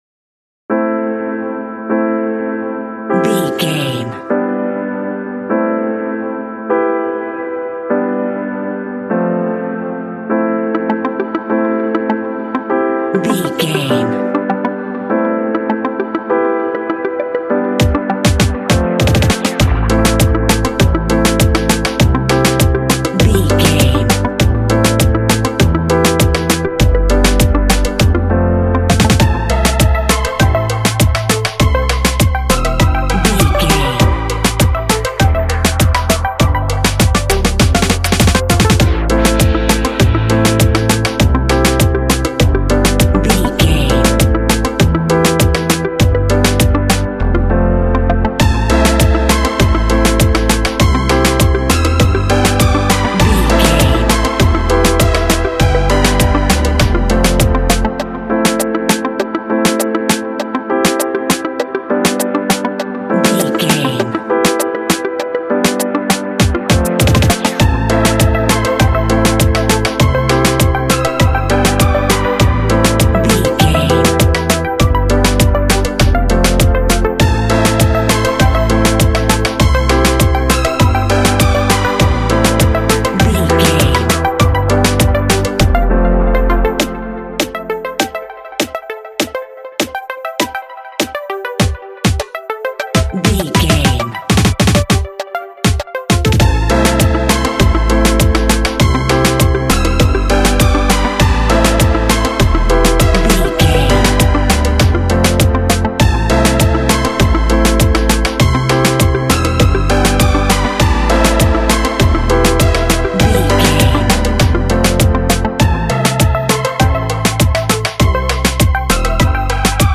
Ionian/Major
warm
sensual
Rhythmic